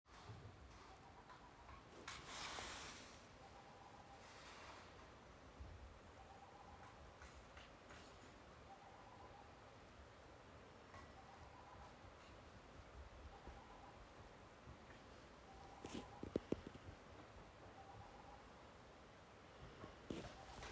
Hvilken fugllyd?
Her hører en flere fugler i bakgrunnen. Jeg er ute etter fuglen som har en rekke med ulike lyder som dukker opp etter 9,5 sek, 19,5 sek og 32 sek.
Høres ut som en litt forsiktig måltrost.